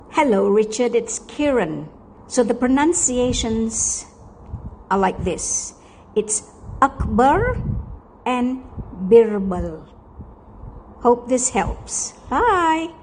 Pronouncing Birbal and Akbar
Birbal-pronunciation.mp3